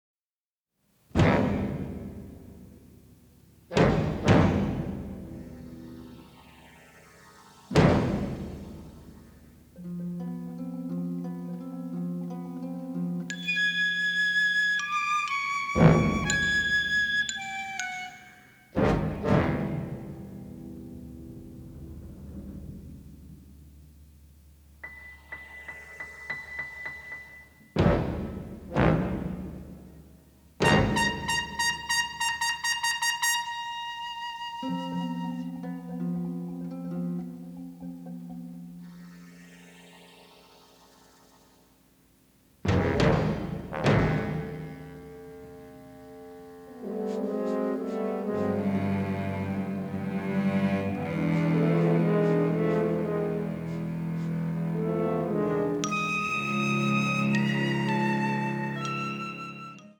Sound quality is excellent.